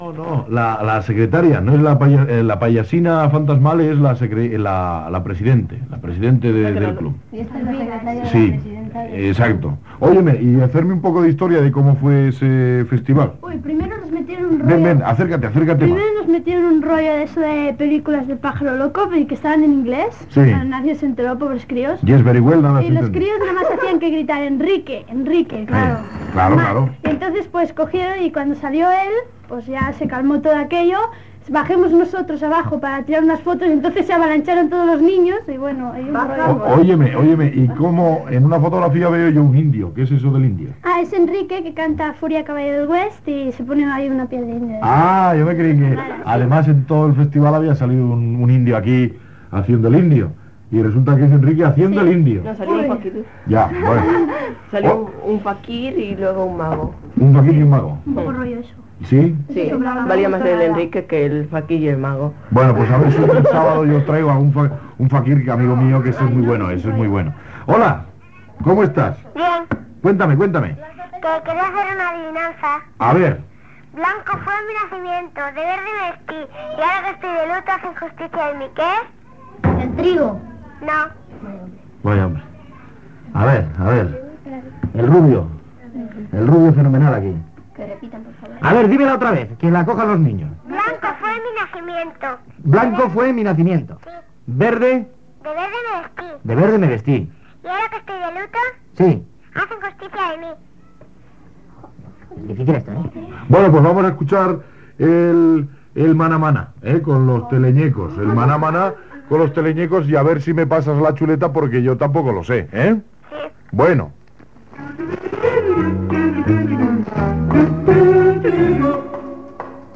Gènere radiofònic Infantil-juvenil